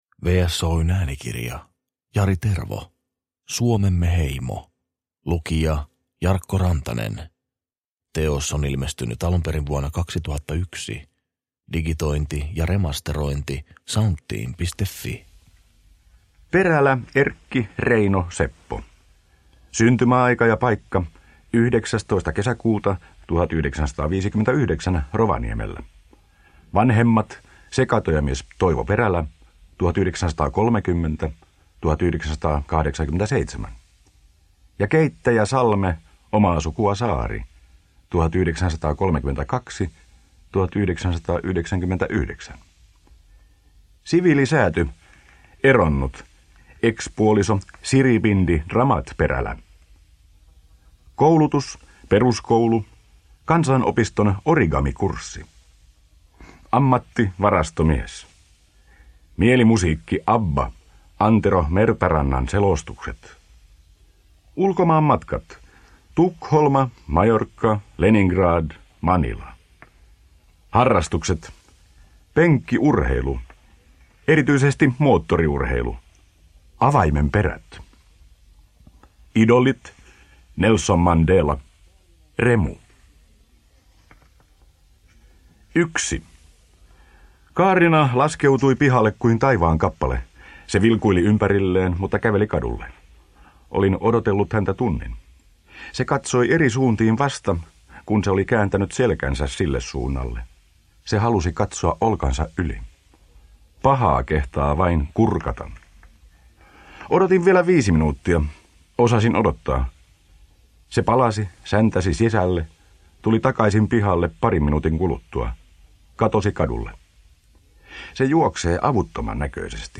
Suomemme heimo – Ljudbok – Laddas ner